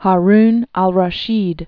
(hä-rn äl-rä-shēd) also Harun ar-Ra·shid (är-) 763?-809.